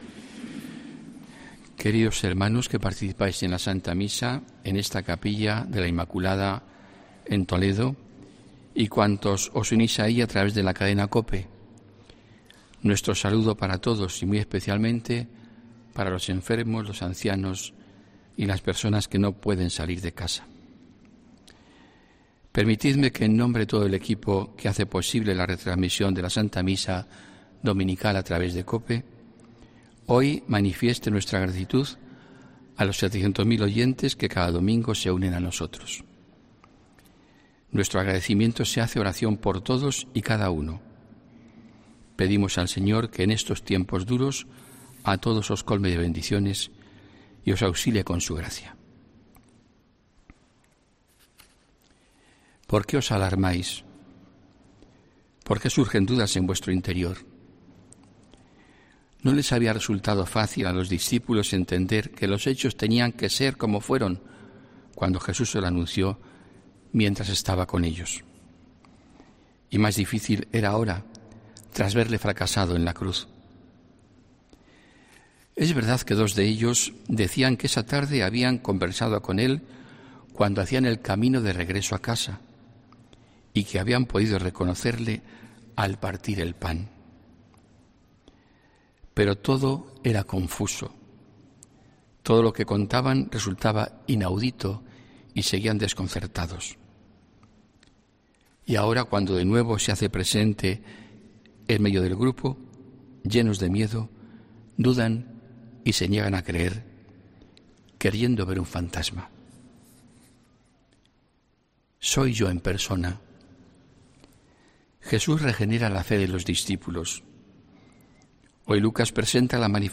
HOMILÍA 18 ABRIL 2021